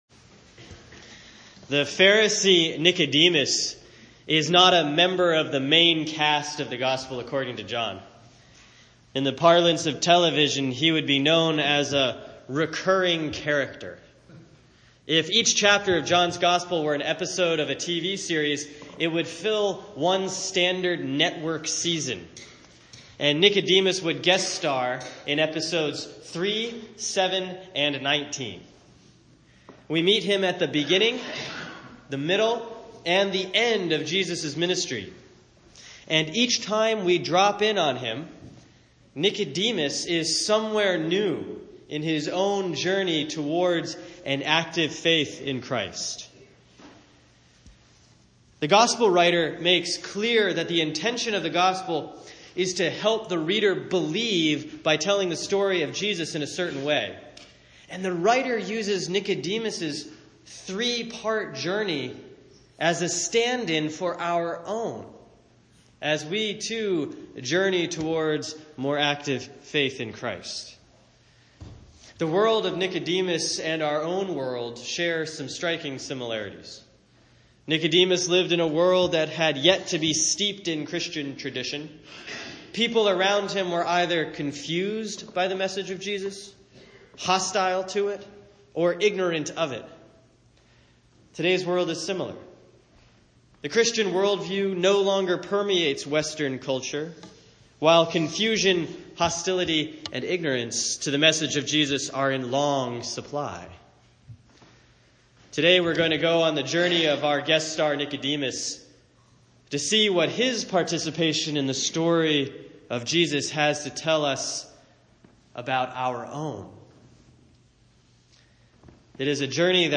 Let's track his appearances in this sermon.